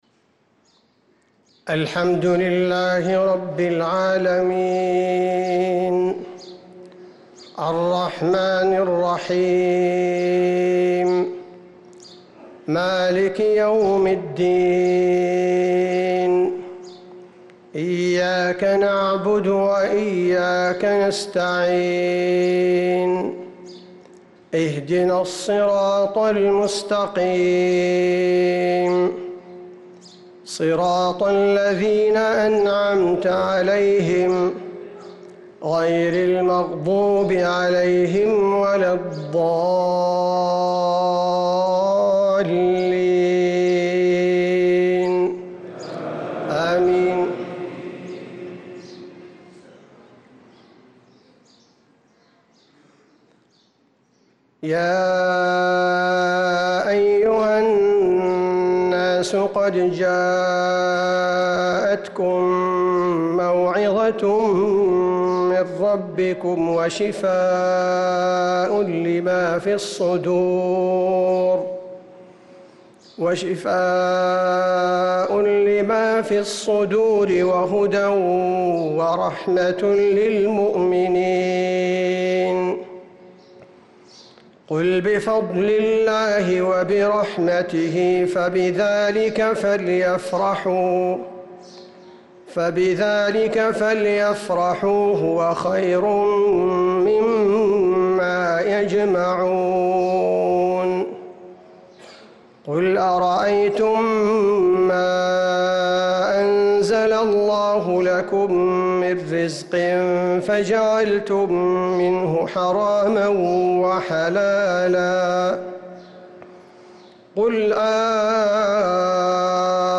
صلاة الفجر للقارئ عبدالباري الثبيتي 29 رمضان 1445 هـ
تِلَاوَات الْحَرَمَيْن .